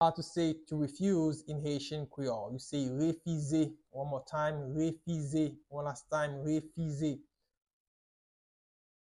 Listen to and watch “Refize” audio pronunciation in Haitian Creole by a native Haitian  in the video below:
24.How-to-say-To-refuse-in-Haitian-Creole-–-Refize-pronunciation.mp3